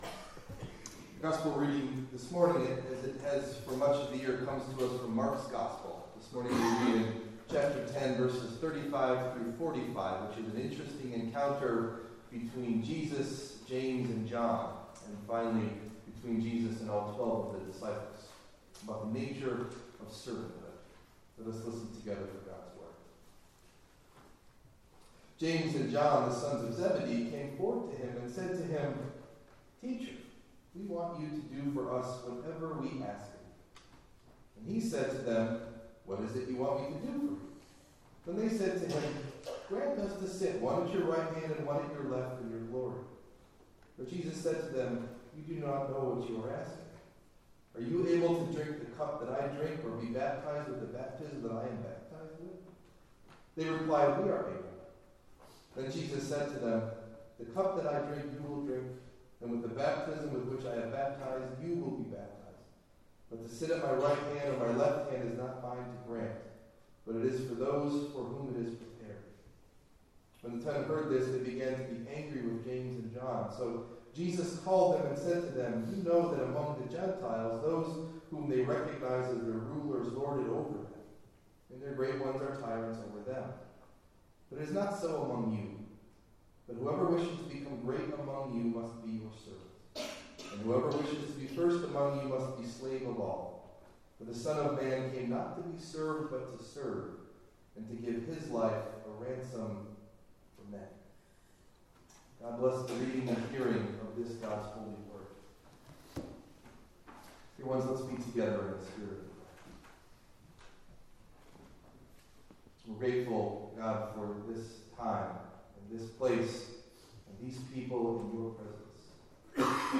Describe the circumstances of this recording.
Sermon Delivered at: The United Church of Underhill (UCC and UMC)